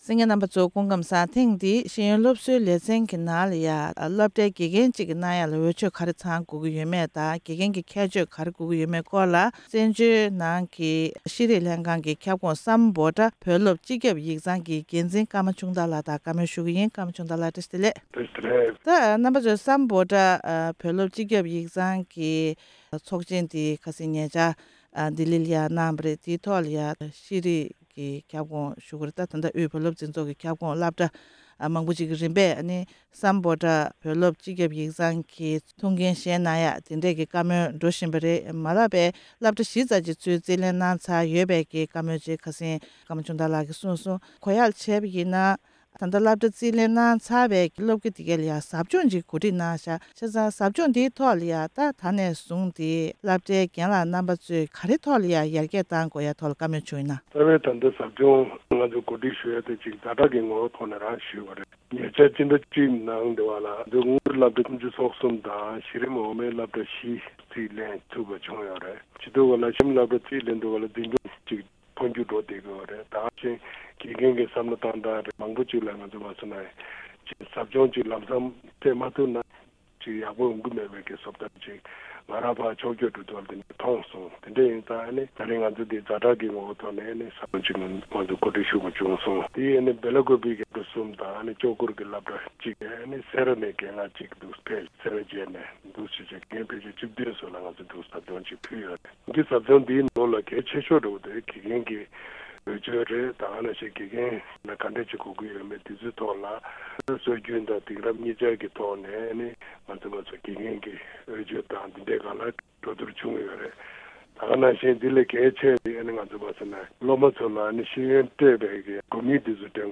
གནས་འདྲི་ཞུས་པར་གསན་རོགས་གནང་།།